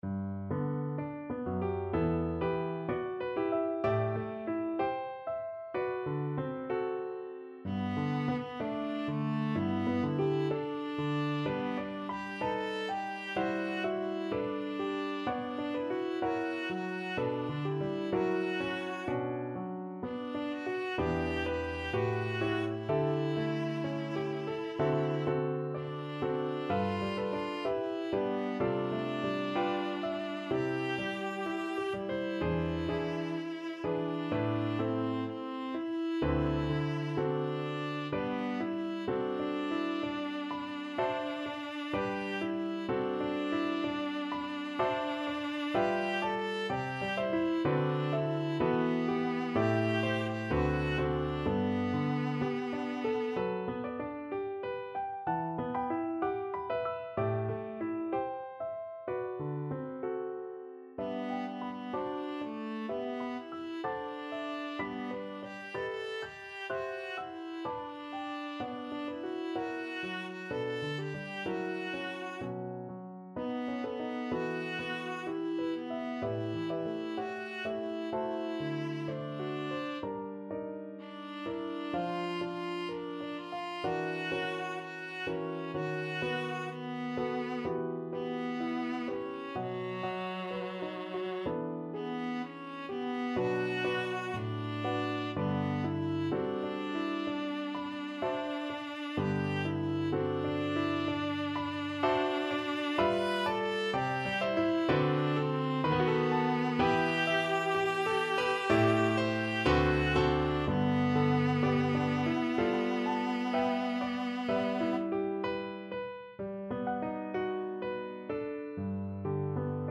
Viola
G major (Sounding Pitch) (View more G major Music for Viola )
Andante moderato poco con moto =63) (View more music marked Andante Moderato)
4/4 (View more 4/4 Music)
Classical (View more Classical Viola Music)